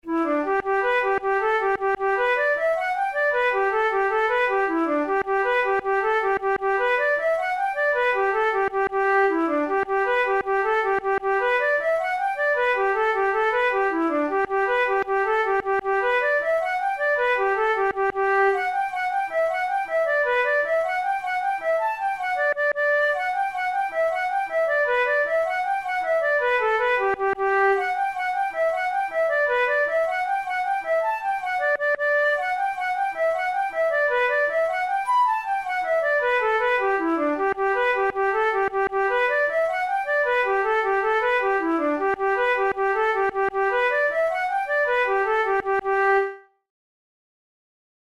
InstrumentationFlute solo
KeyG major
Time signature6/8
Tempo104 BPM
Jigs, Traditional/Folk
Traditional Irish jig